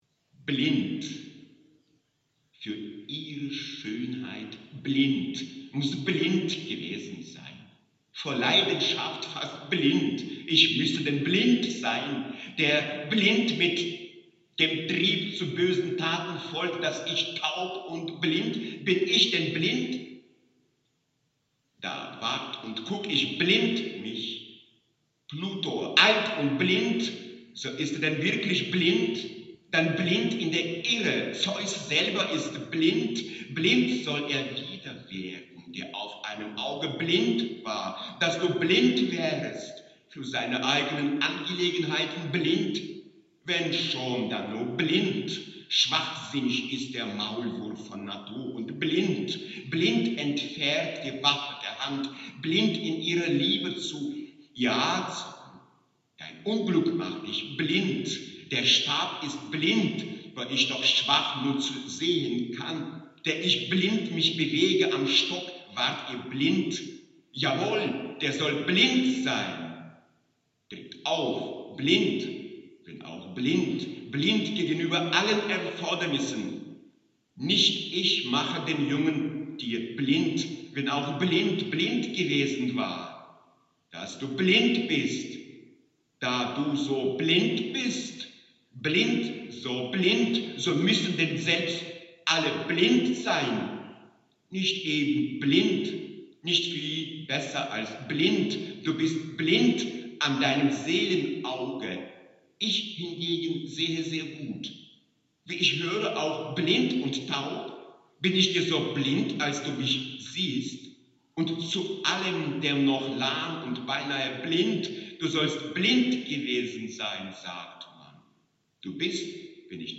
„>>blind<<“, das eine Montage aus verschiedenen übersetzten antiken Textstellen die das Wort enthalten ist, versucht das Gestische durch Redundanz auszusteuern und klanglich – würde ich sagen – einen dubstep hinzulegen; jede Erzählung die dabei entstehen könnte ist rein zufällig.